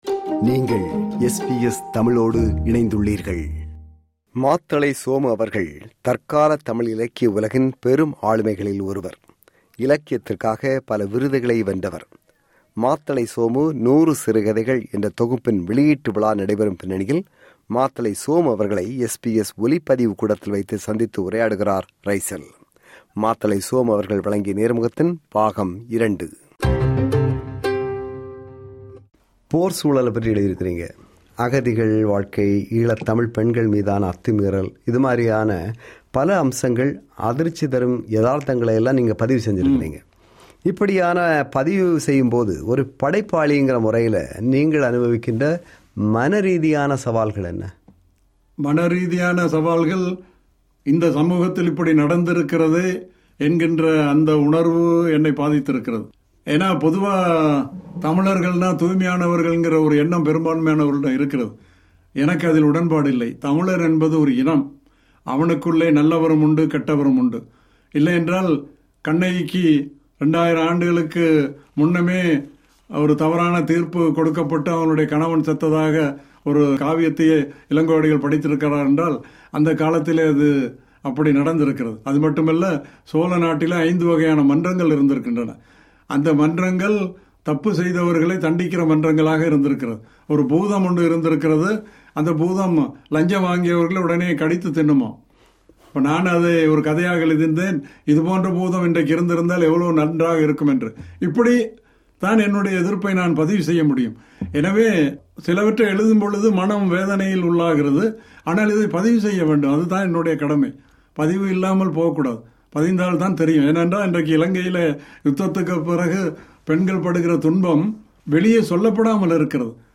SBS ஒலிப்பதிவு கூடத்தில் வைத்து சந்தித்து உரையாடுகிறார்
நேர்முகம் பாகம்: 2